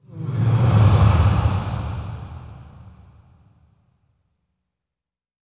SIGHS 1   -L.wav